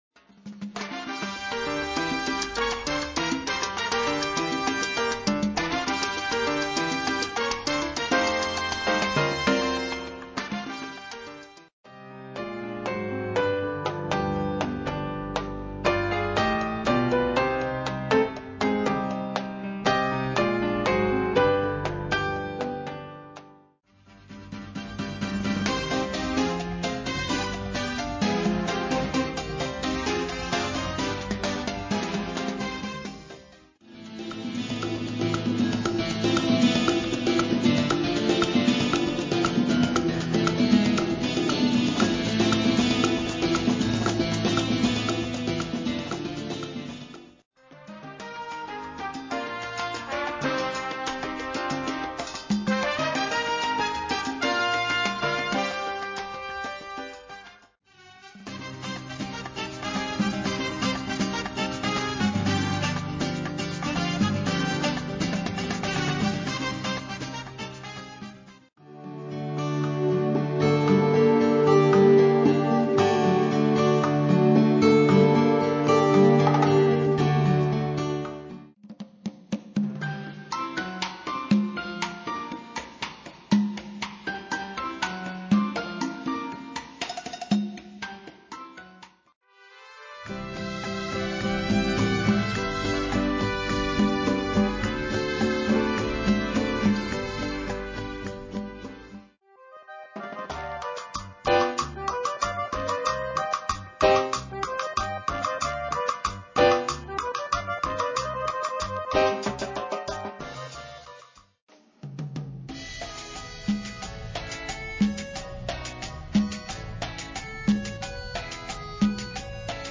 Latin Dance Collection